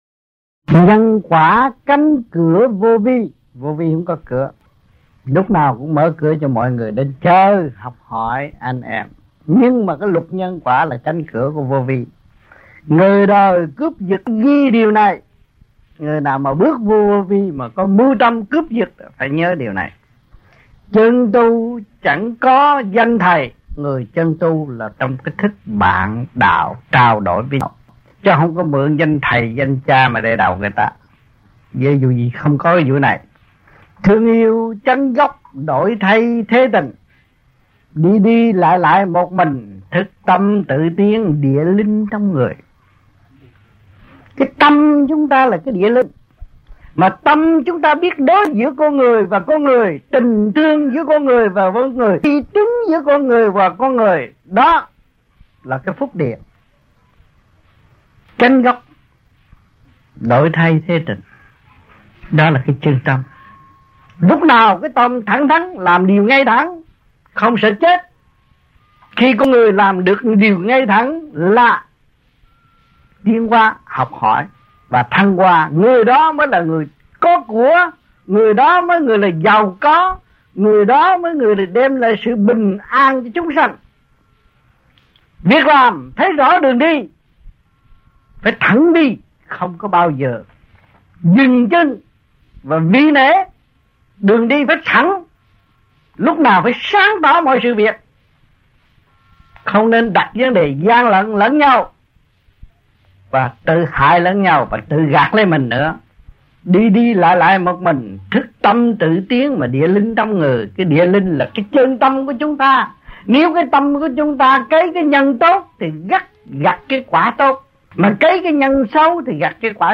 Bankstown, New South Wales, Australia
Sinh hoạt thiền đường